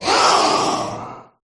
thievul_ambient.ogg